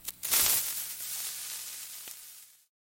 fuse.mp3